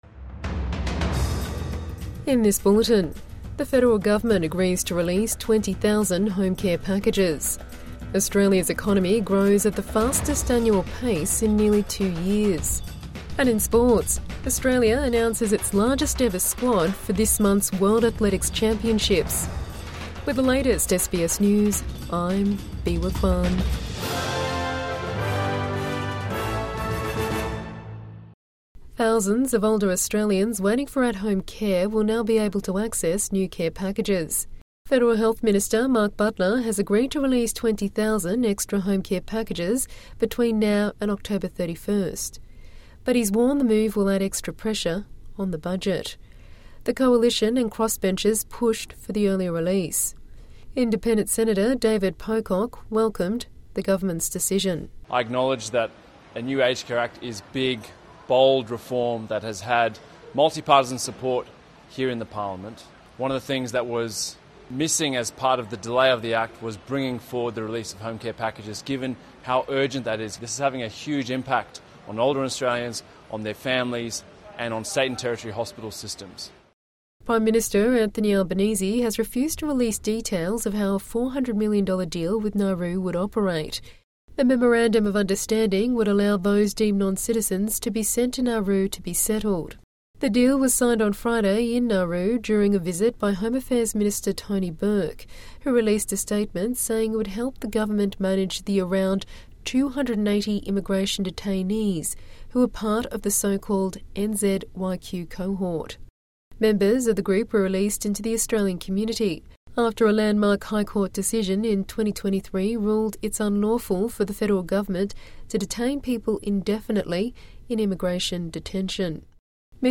20,000 home care packages to be delivered before end of October | Evening News Bulletin 3 Sep 2025 4:43